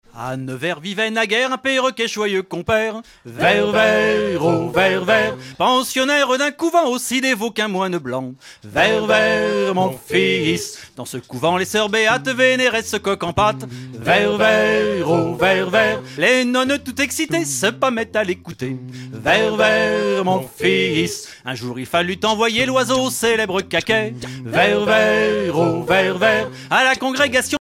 Genre strophique
Concert donné en 2004
Pièce musicale inédite